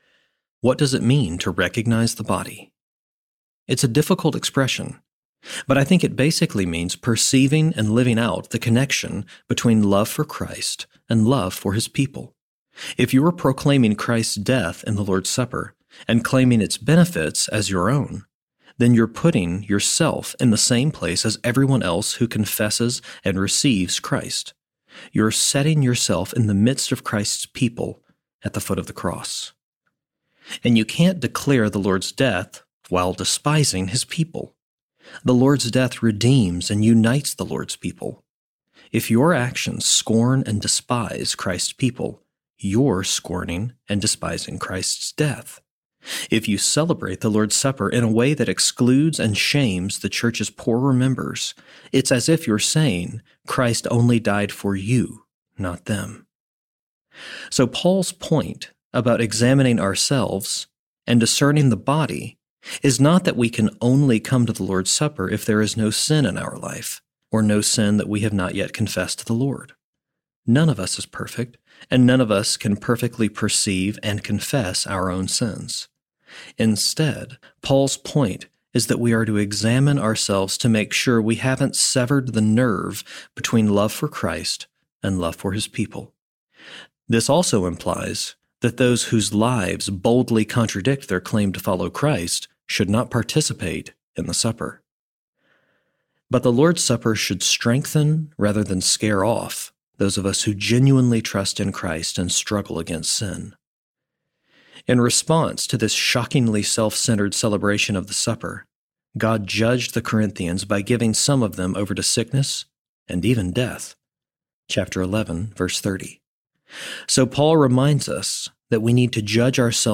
Understanding The Lord’s Supper (Church Basics Series) Audiobook
1.85 Hrs. – Unabridged